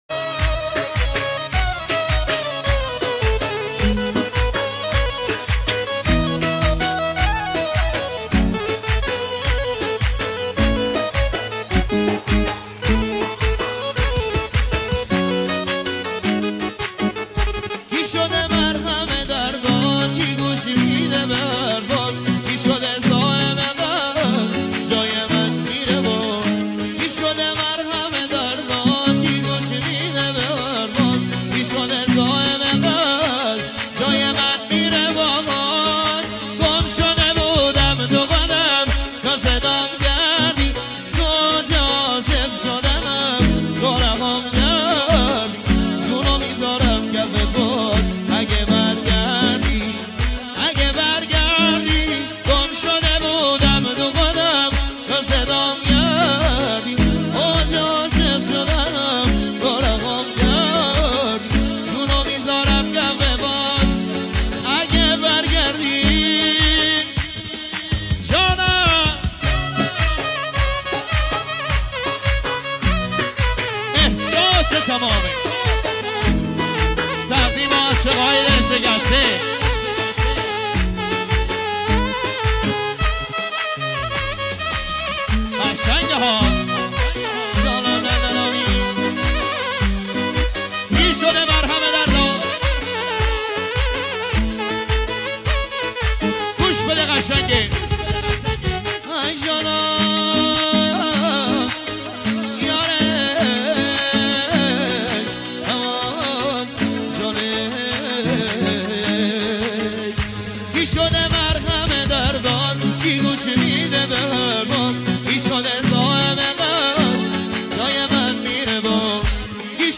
ترانه بسیار سوزناک و احساسی